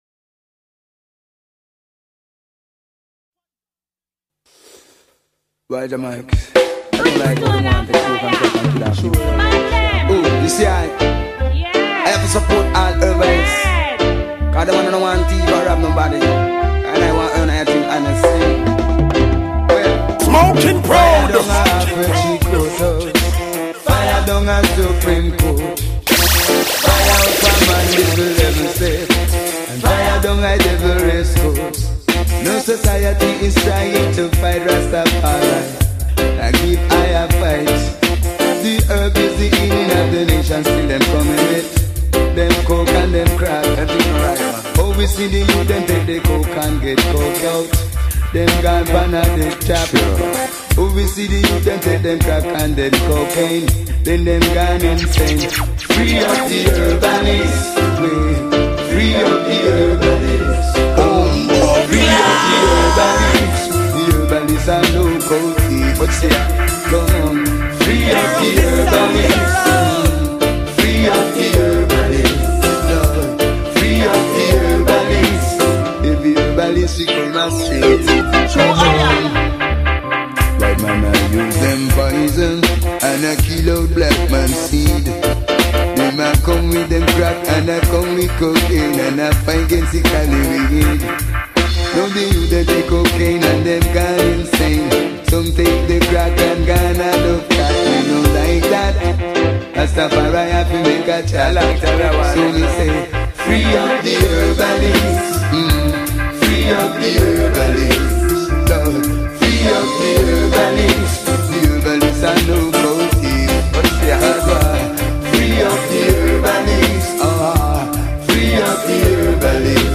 varr herb tracks mix light one up